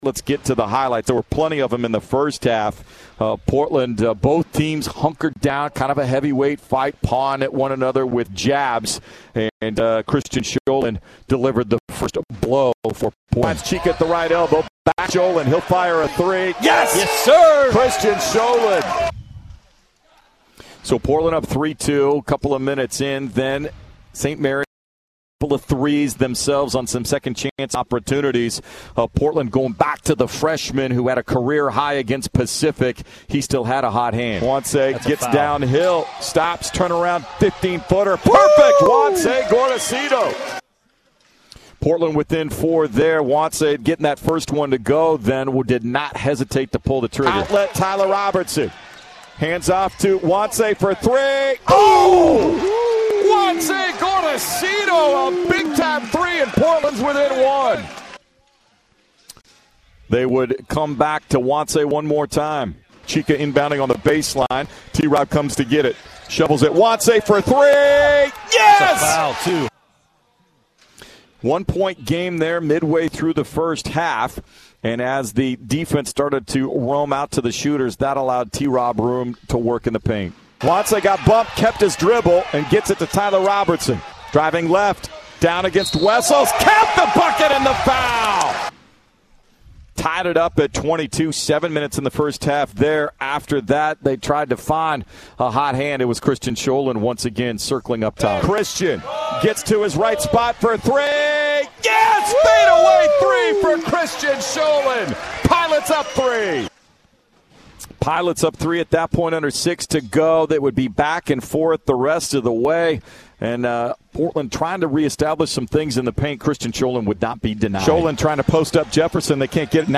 Postgame radio highlights
Men's Basketball Radio Interviews